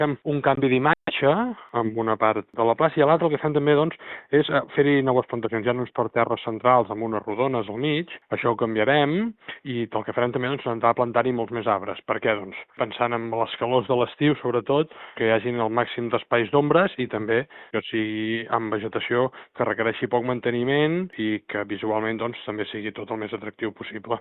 L’alcalde Marc Buch ha explicat a RCT en què consistirà la transformació que es farà a la zona delimitada pel Rierany dels Frares i el carrer Àngel Guimerà, al costat de la via del tren.